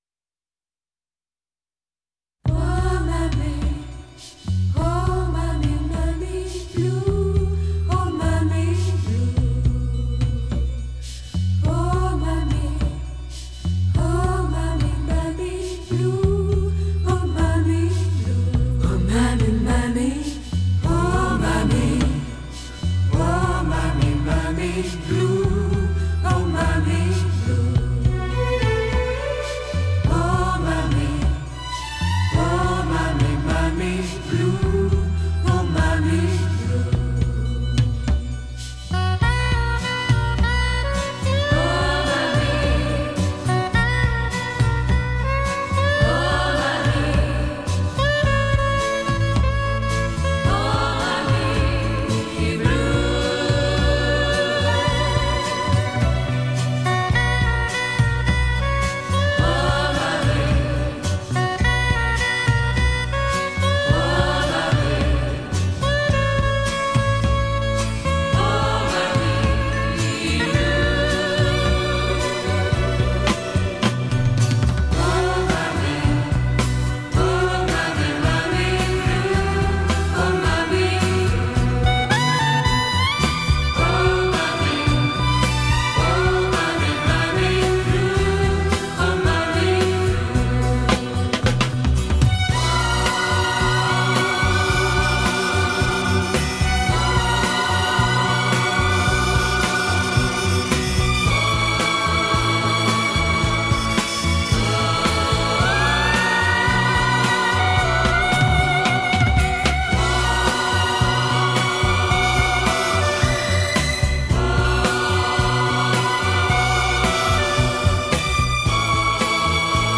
在于结构简单，节奏明快，旋律动听，具有亲切明了的内涵，